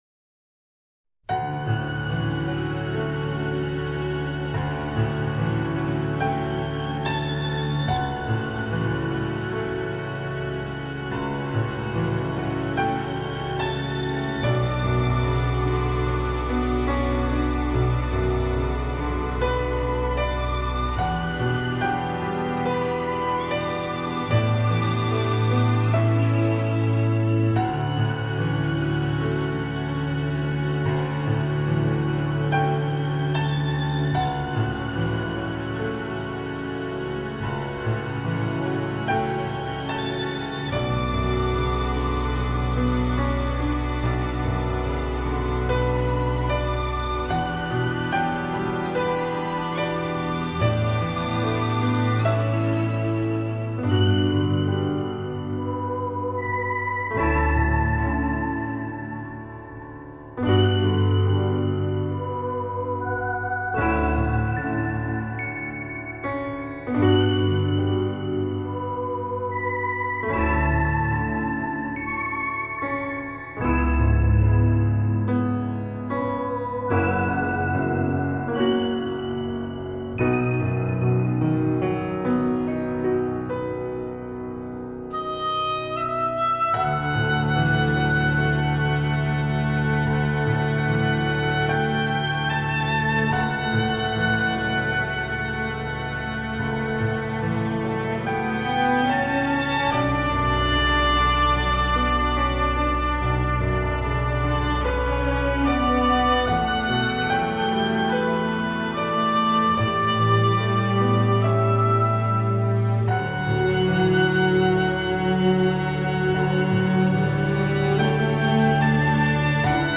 这张专辑中的乐曲充满了新古典主义乐风